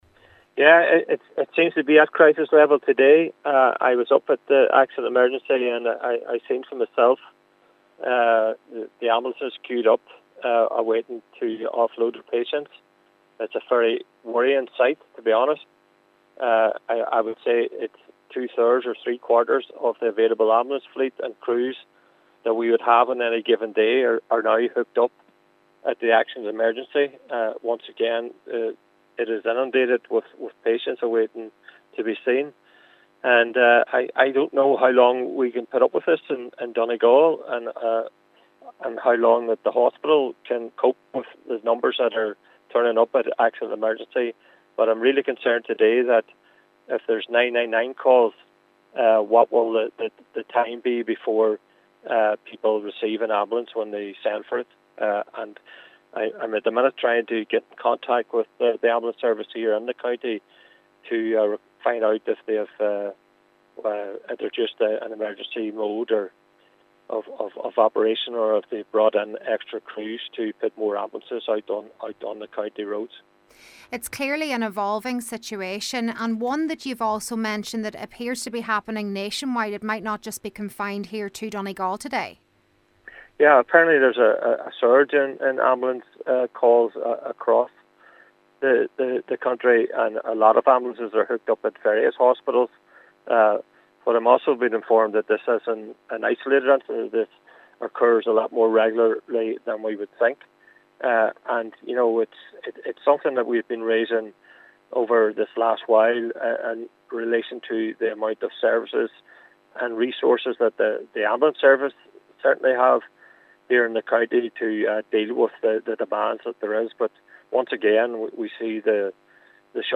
Cllr McMonagle says this in turn could lead to delays in other emergencies being attended to and is demanding urgent clarity as to whether extra resources are being called in from elsewhere: